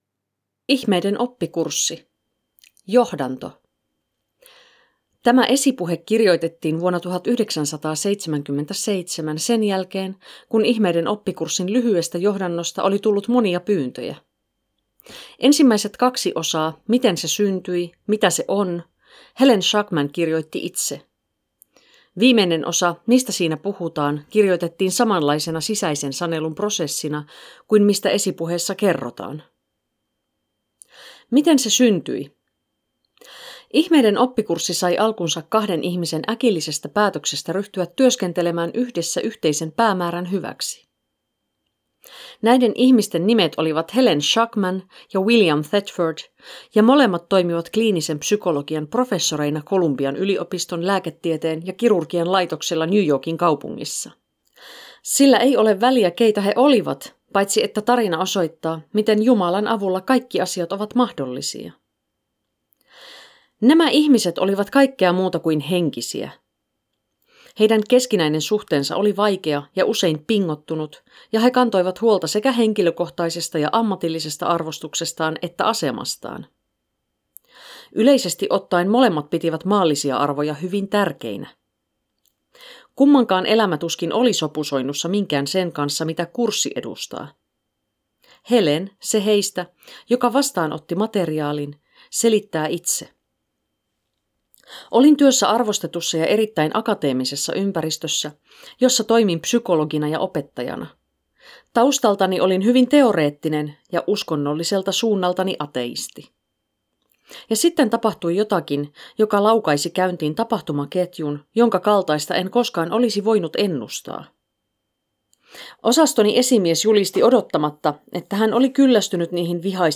Announcing the Finnish audiobook!
recorded in Finland